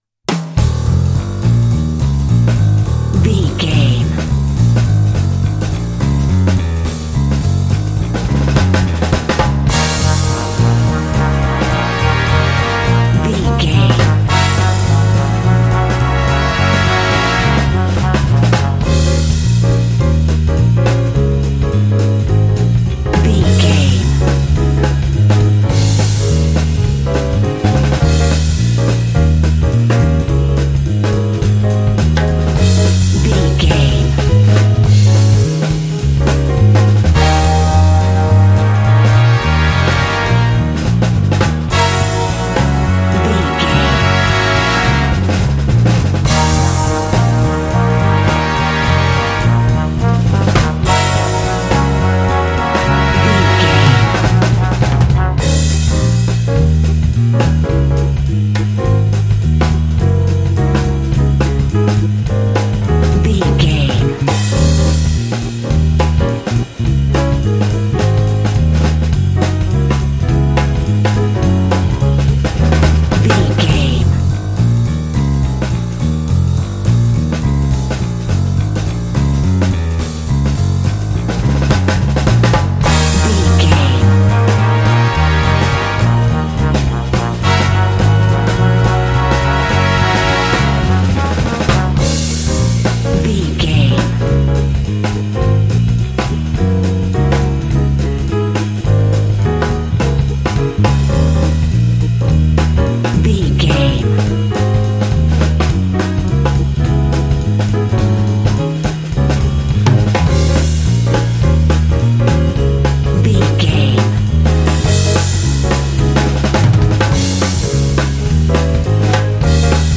Aeolian/Minor
E♭
energetic
groovy
lively
bass guitar
piano
drums
brass
jazz
big band